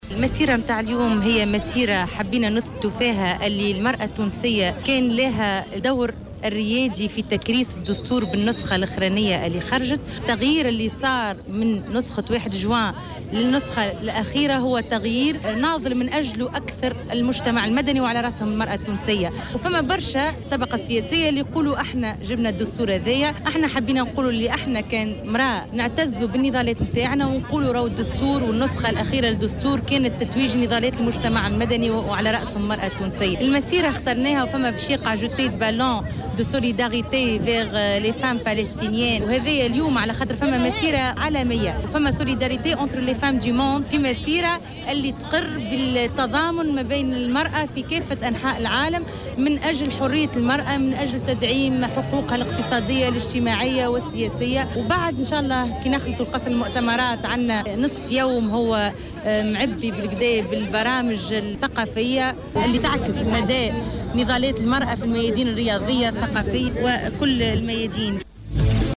في تصريح ل"جوهرة أف أم" على هامش هذه المسيرة